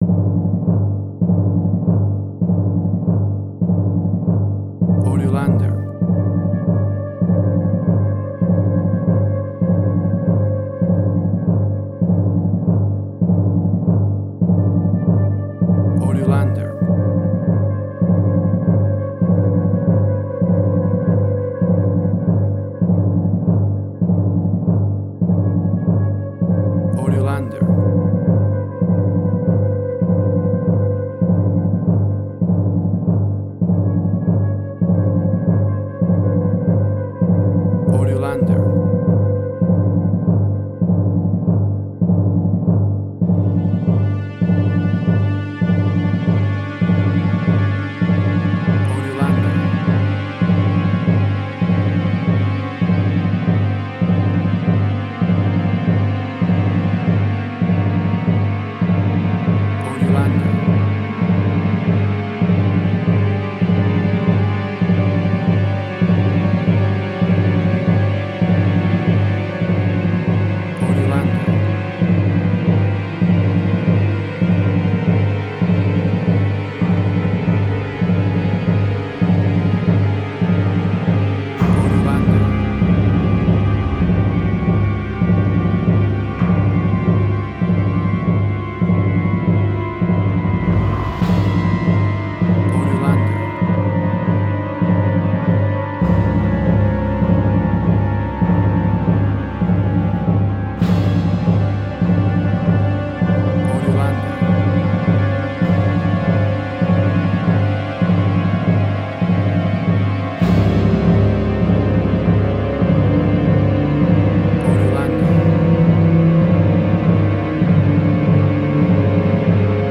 Dissonance
Tempo (BPM): 100